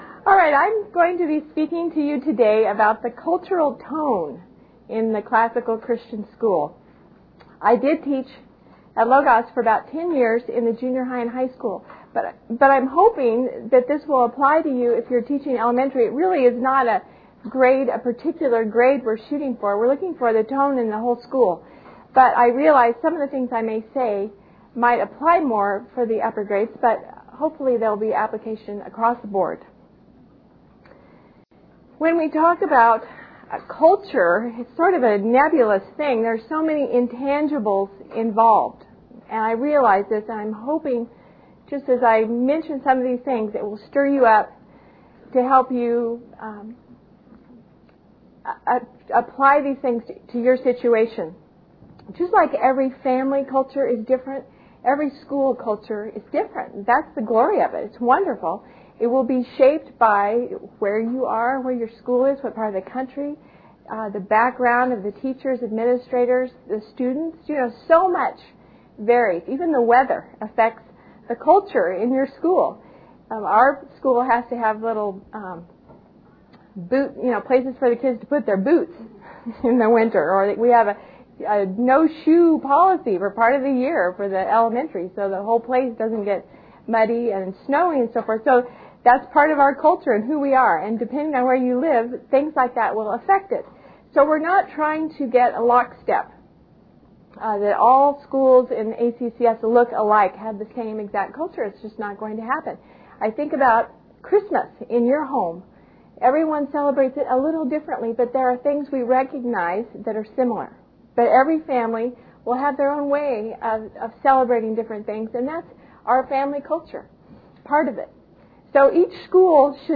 2004 Workshop Talk | 0:54:08 | Culture & Faith